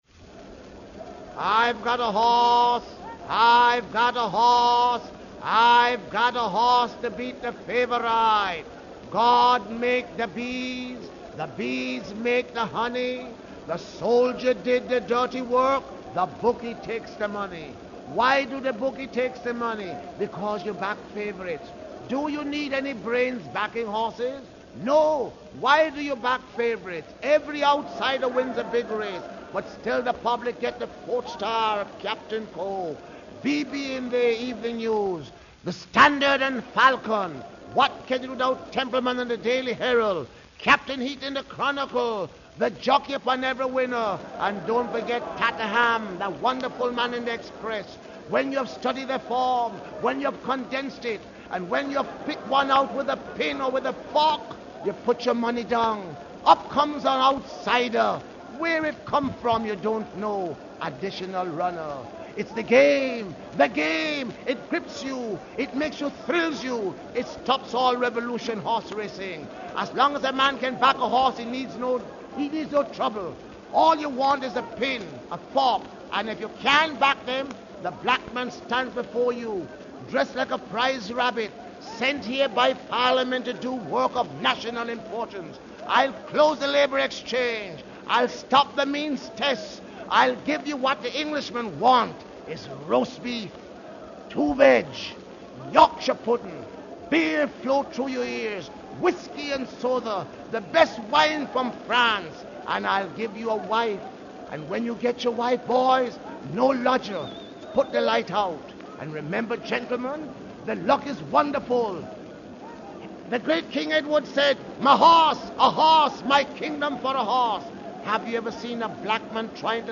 Dressed in ornate clothing wearing ostrich feathers he would cry " I gotta horse, I gotta horse".
MonoluluIgottahorse.mp3